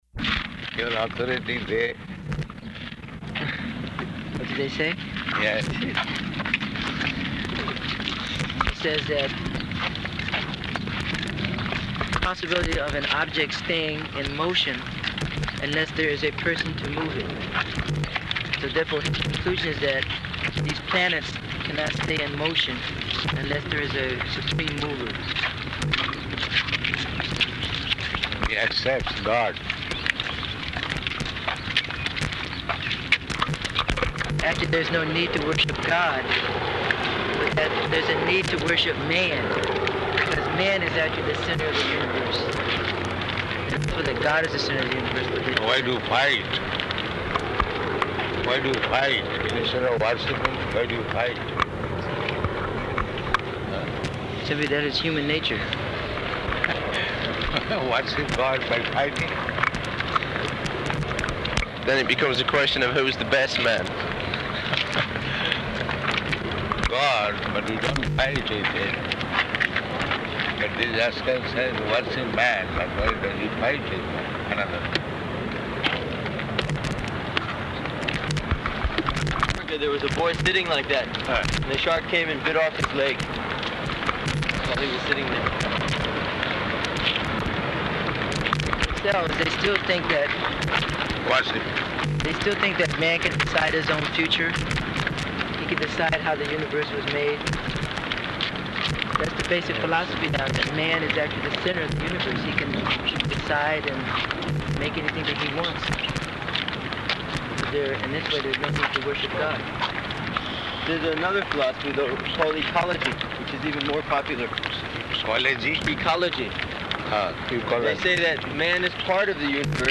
Morning Walk [partially recorded]
Type: Walk
Location: Honolulu